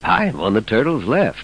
00214_Sound_Tiger-1